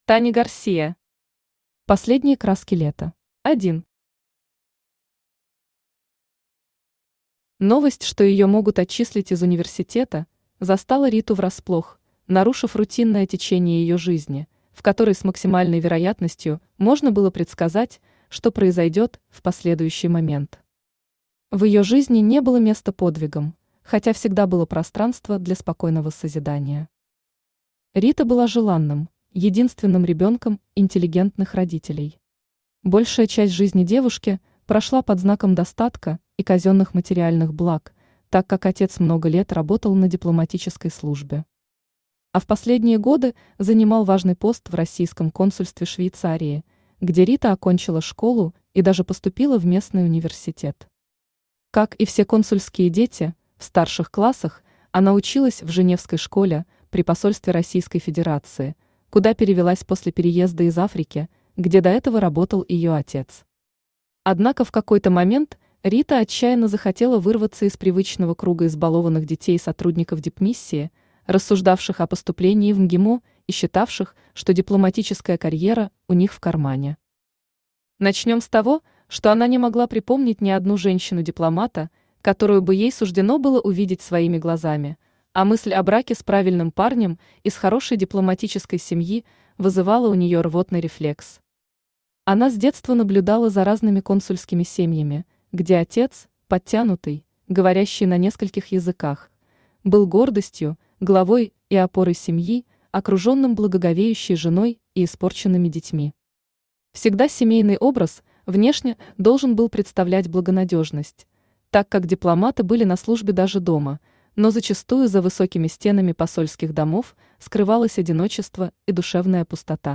Aудиокнига Последние краски лета Автор Таня Гарсия Читает аудиокнигу Авточтец ЛитРес.